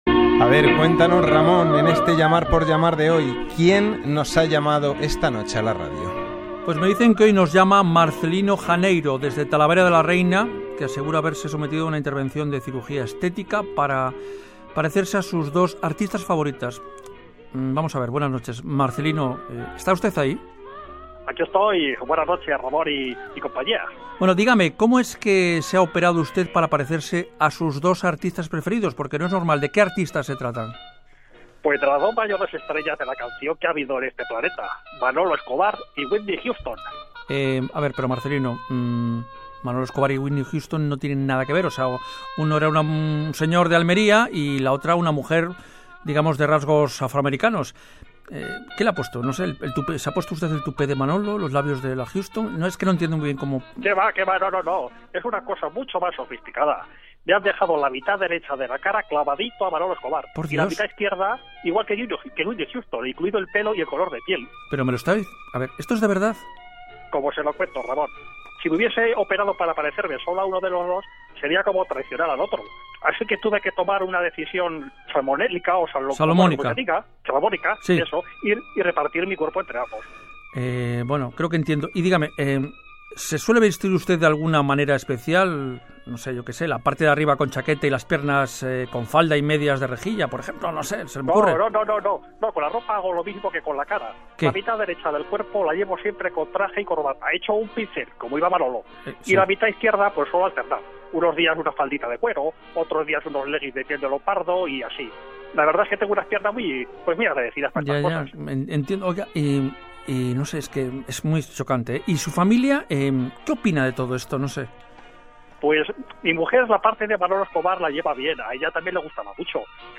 entrevista en el programa Gente despierta (Radio Nacional de España) a un hombre que se ha hecho la cirugía estética para parecerse a sus dos ídolos a la vez